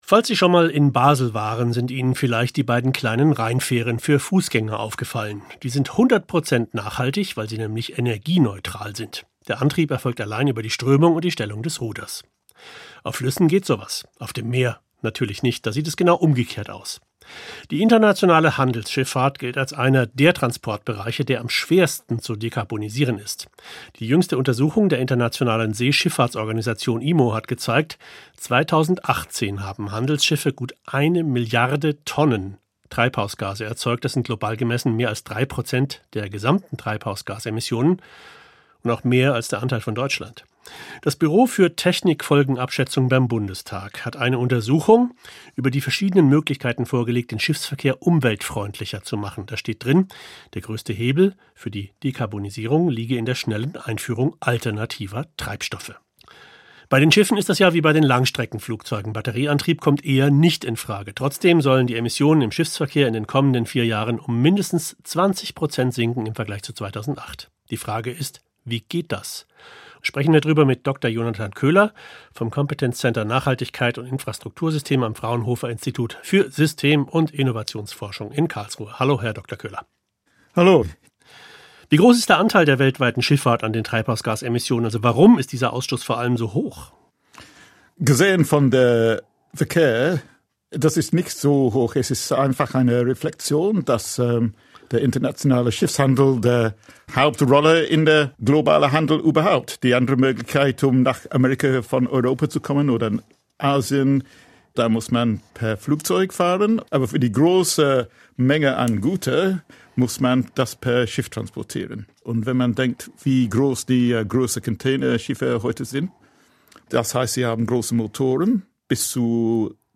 Gespräch